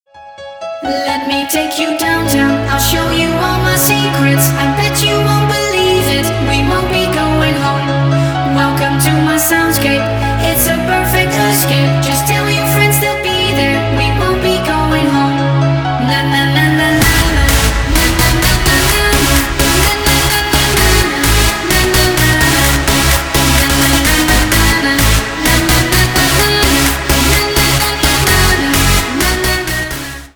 Клубные рингтоны
рингтоны громкие